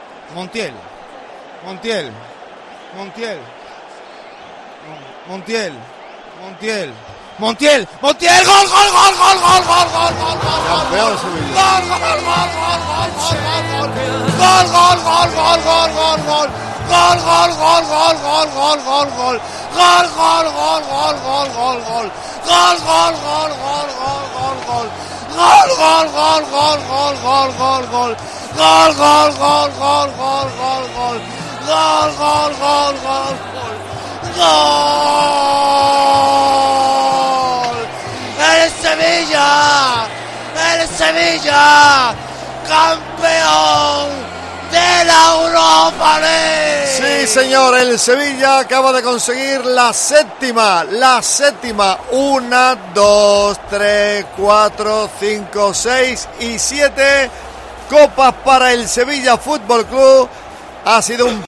Escucha con la narración de COPE Sevilla el último penalti que da el triunfo al Sevilla FC